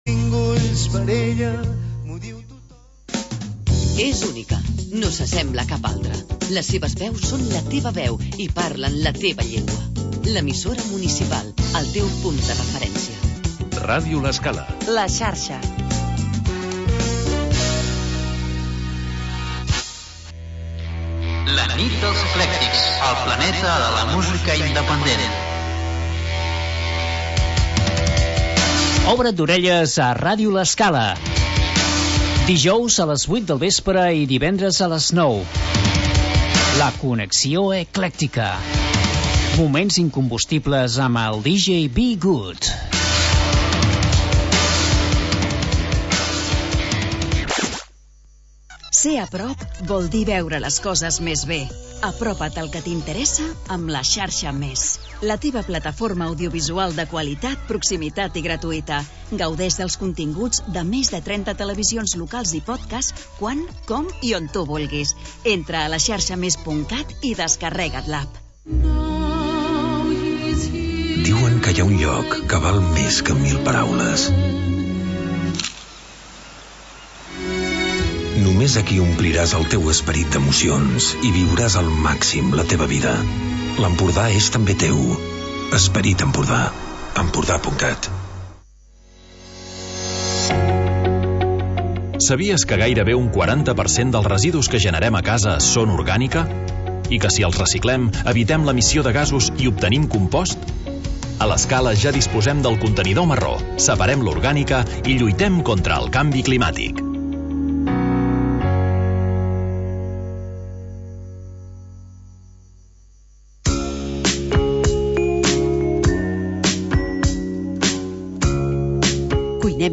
Magazín d'entreteniment per acompanyar el migdia